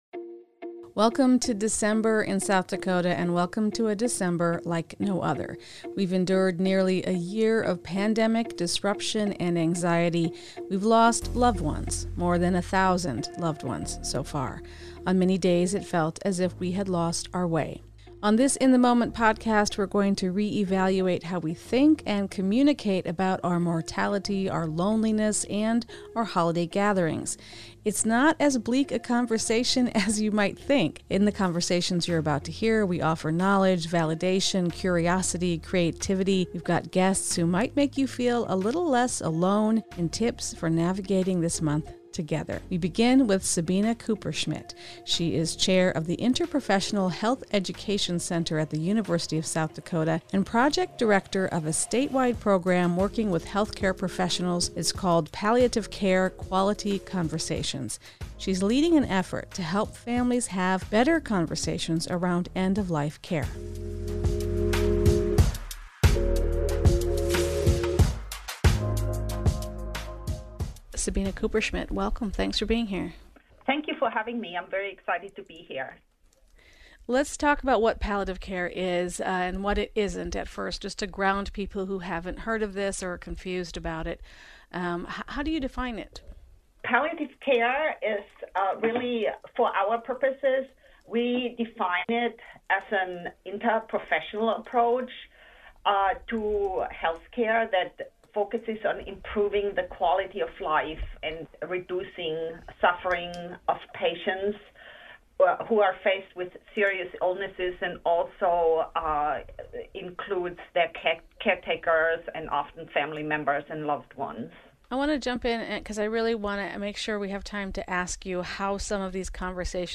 We’ve got guests who might make you feel a little less alone and tips for navigating this month together: